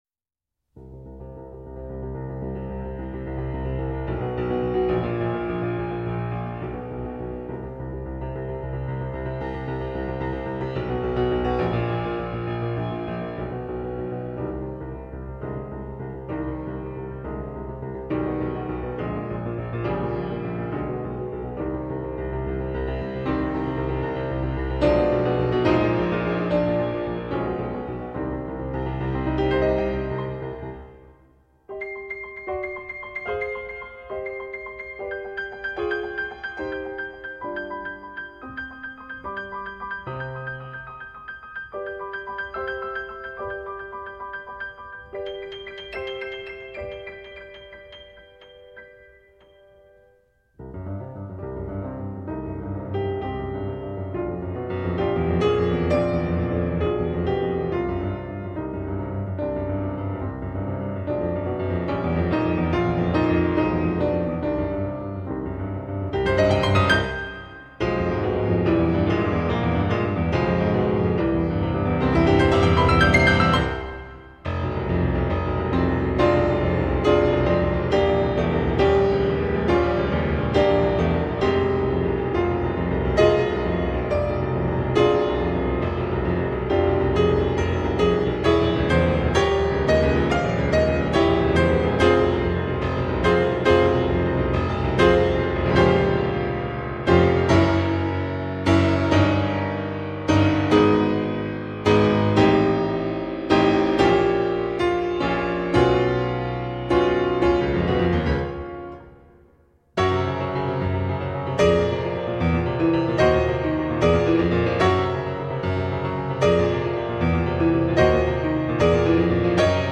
programová miniatúra Klavír